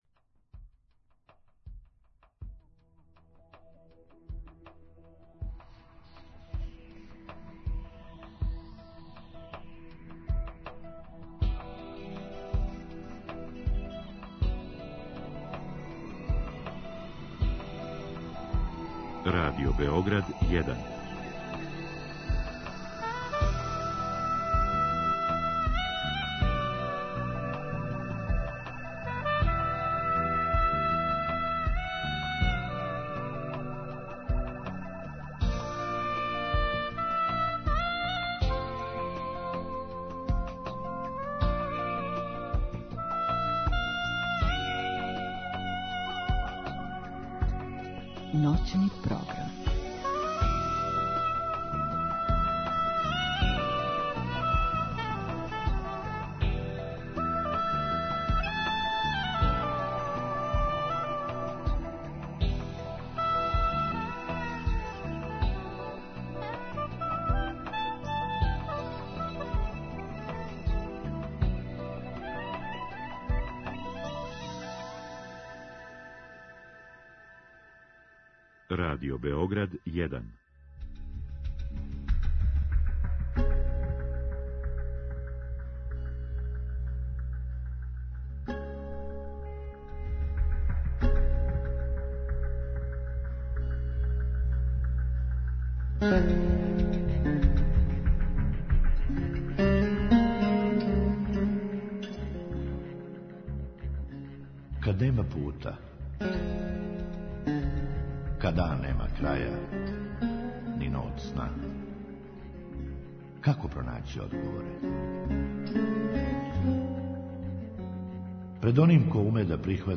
У другом сату слушаоци могу поставити питање гошћи у вези са темом и то директним укључењем у програм или путем Инстаграм странице емисије.